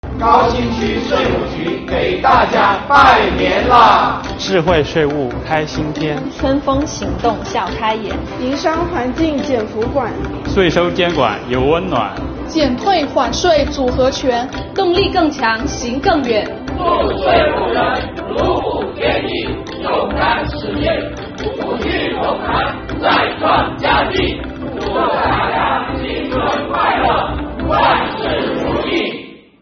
值此新春佳节之际广西各地税务干部用短视频向您送来最诚挚的新春祝福。